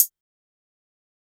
HiHat (15).wav